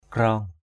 /krɔ:ŋ/